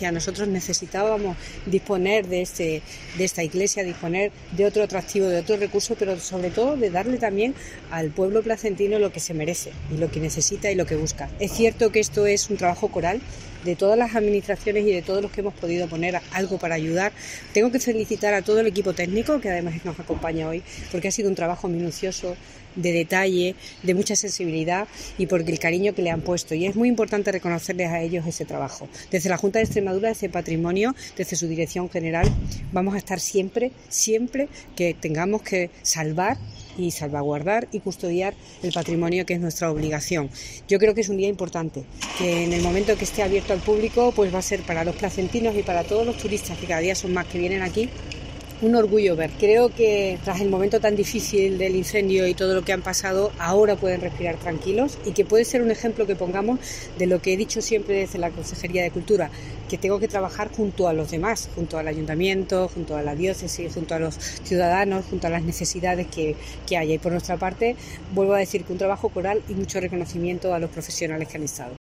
Victoria Bazaga, consejera de Cultura, Turismo, Jóvenes y Deportes